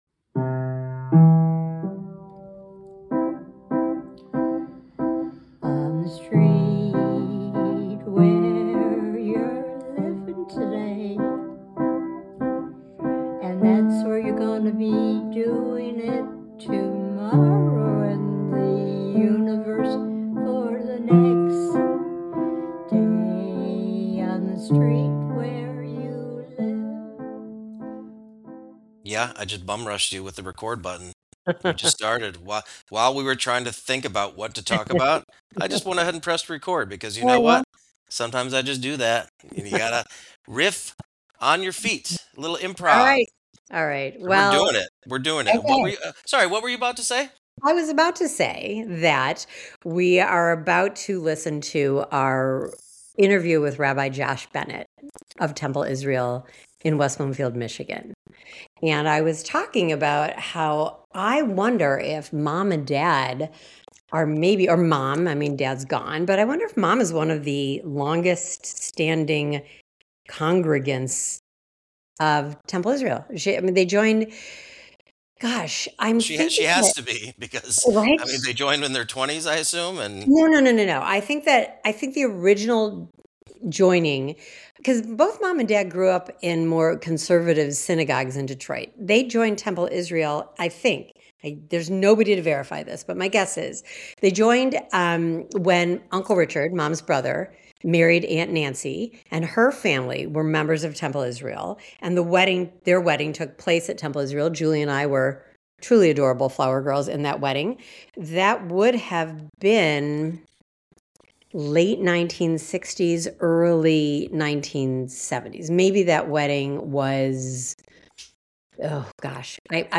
Join us for an insightful conversation